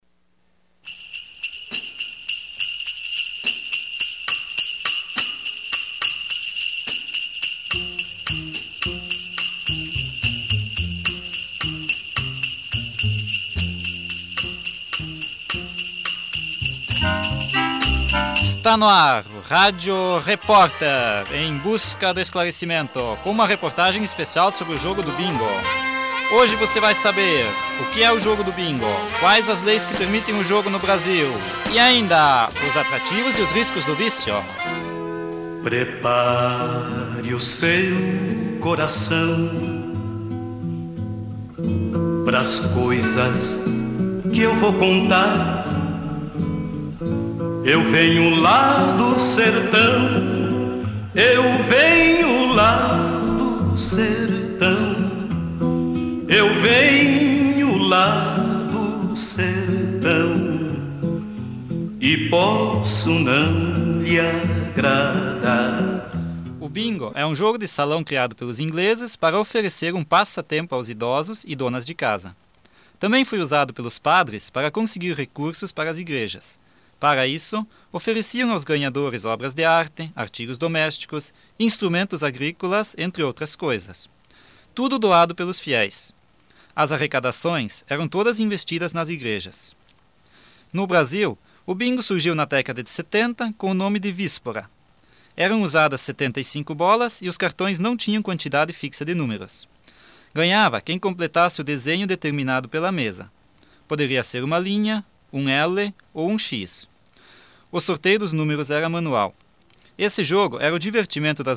Uma reportagem especial sobre o jogo do bingo.